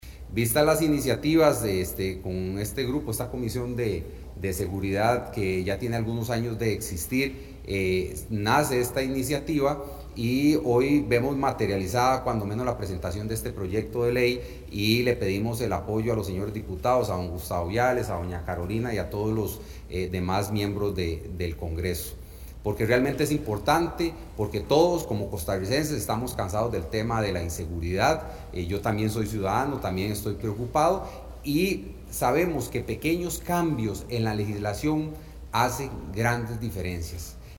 Por su parte, el ministro de Seguridad Pública Michael Soto, manifestó que el proyecto es importante para reforzar la seguridad.